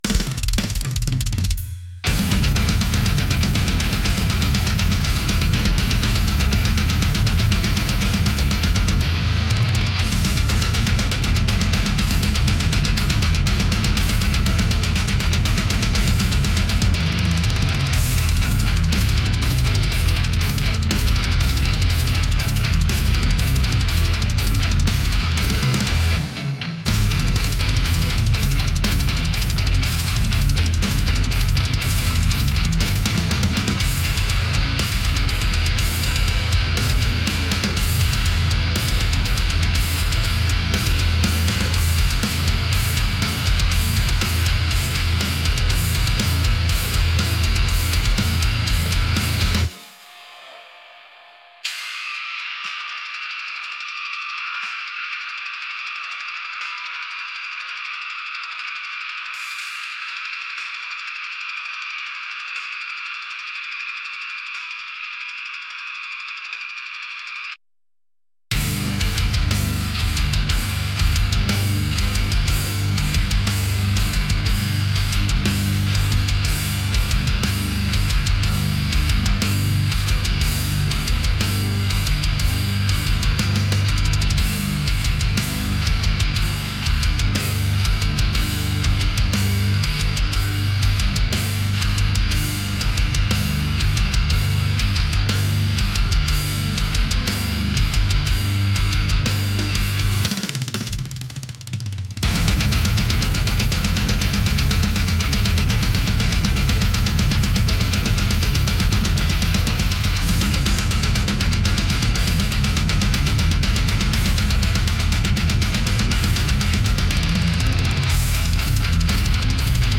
aggressive | metal | intense